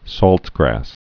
(sôltgrăs)